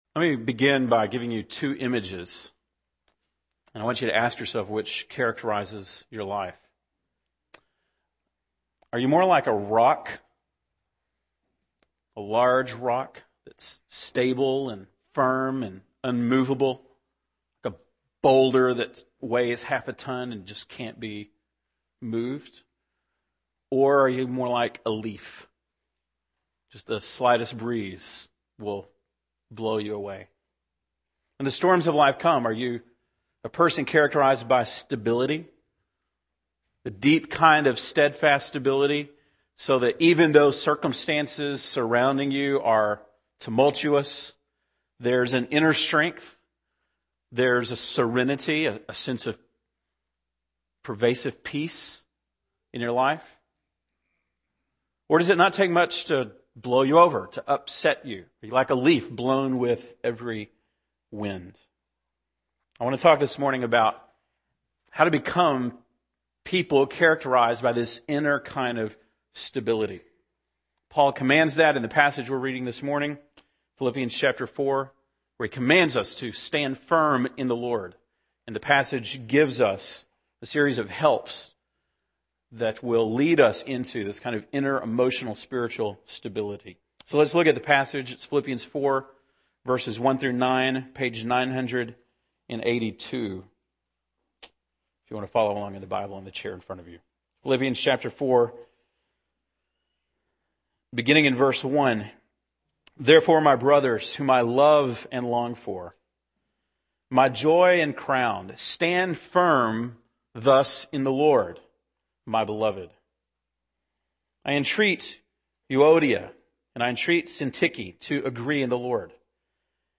November 15, 2009 (Sunday Morning)